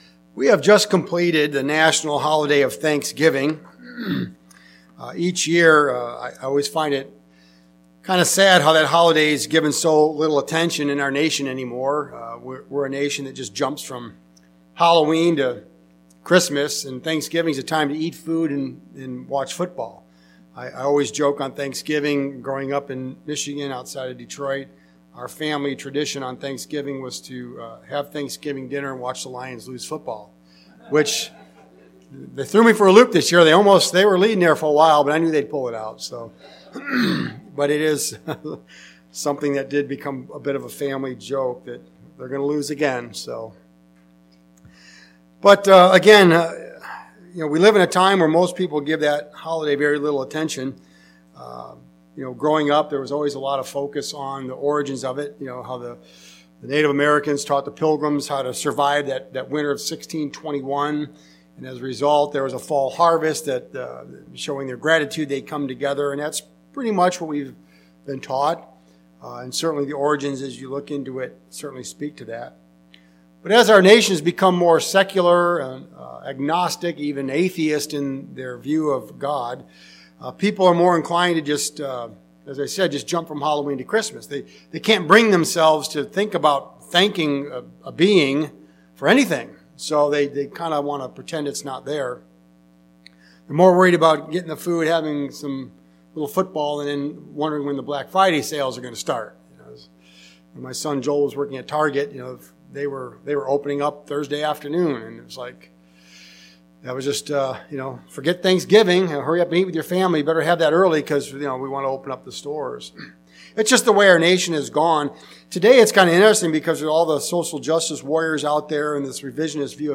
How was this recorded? Given in Mansfield, OH